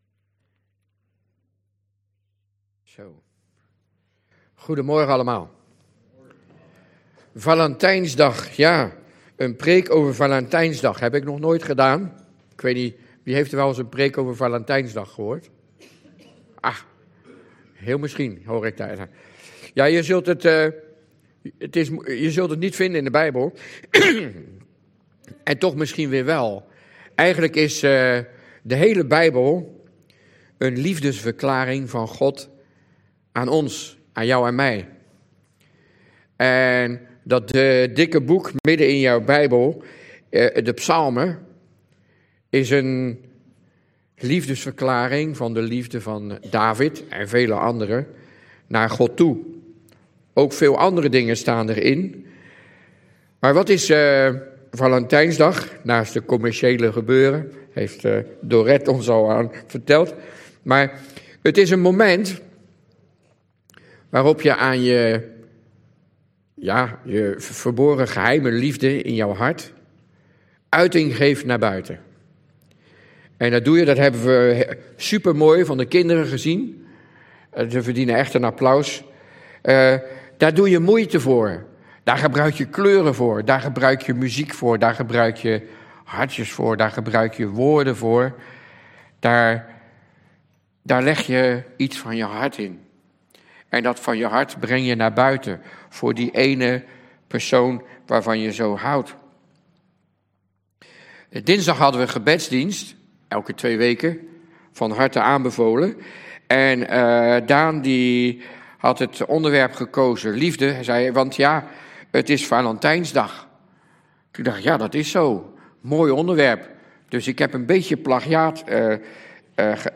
Toespraak 19 februari: Ik houd van jou! - De Bron Eindhoven